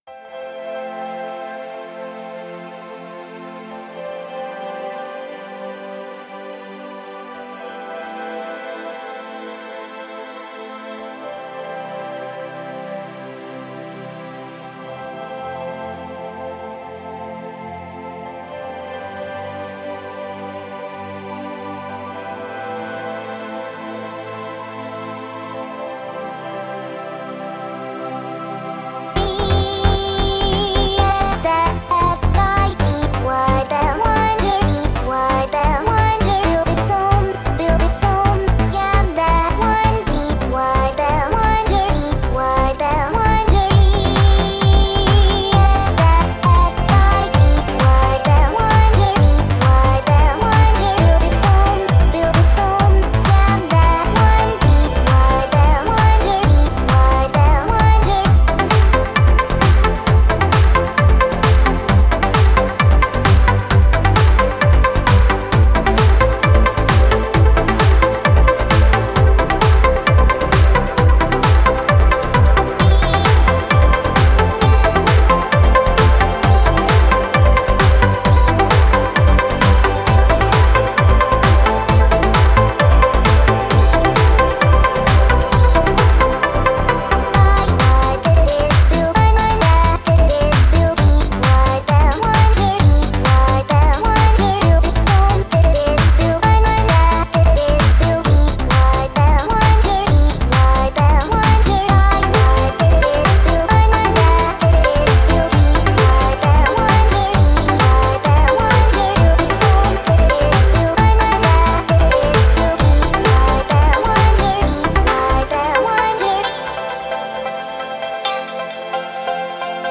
nicely done...the blend